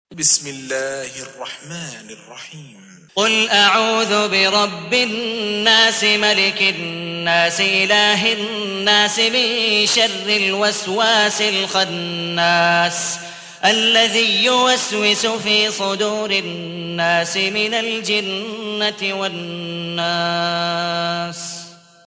تحميل : 114. سورة الناس / القارئ عبد الودود مقبول حنيف / القرآن الكريم / موقع يا حسين